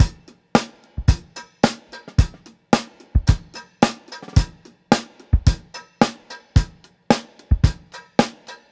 • 110 Bpm Drum Beat C# Key.wav
Free drum loop sample - kick tuned to the C# note. Loudest frequency: 929Hz
110-bpm-drum-beat-c-sharp-key-UZy.wav